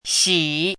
chinese-voice - 汉字语音库
xi3.mp3